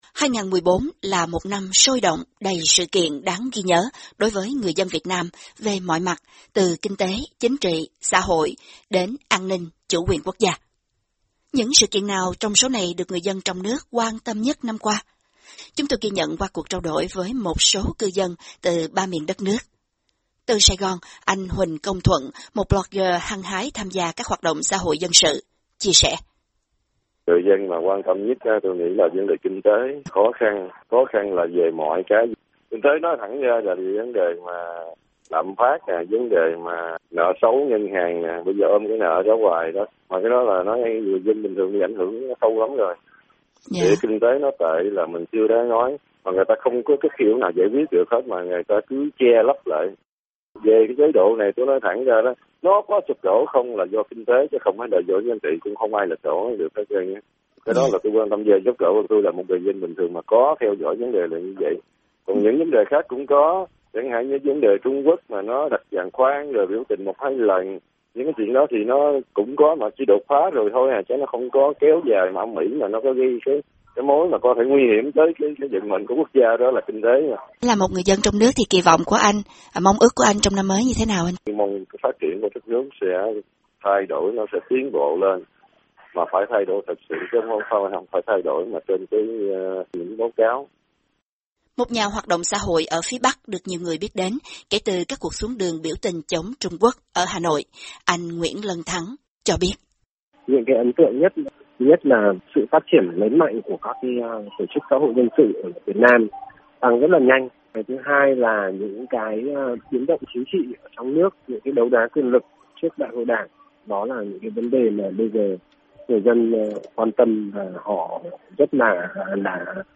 Những sự kiện nào trong số này được dân chúng trong nước quan tâm nhất năm qua? Chúng tôi ghi nhận qua cuộc trao đổi với một số cư dân từ ba miền đất nước.
Vừa rồi là ý kiến của một số cư ở ba miền đất nước Bắc, Trung, Nam mà chúng tôi có dịp hỏi chuyện trong cuộc ghi nhận ngắn trong ngày cuối năm.